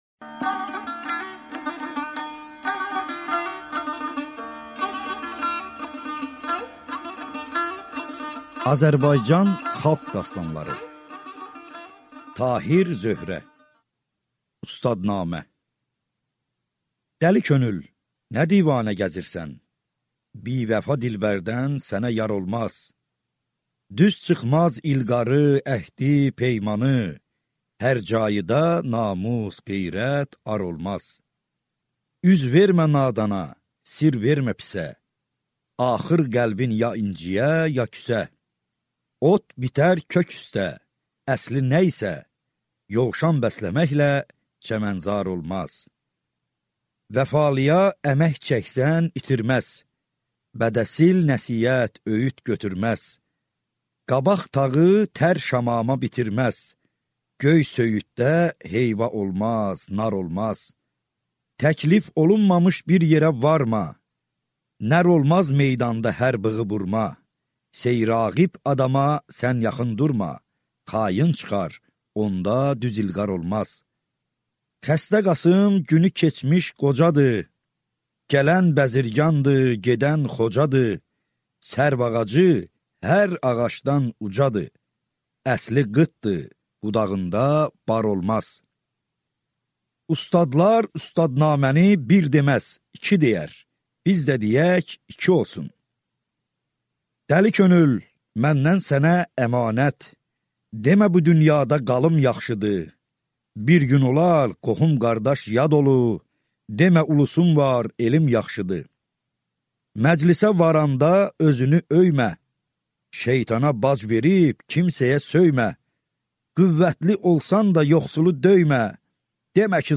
Аудиокнига Azərbaycan xalq dastanları 2-ci hissə | Библиотека аудиокниг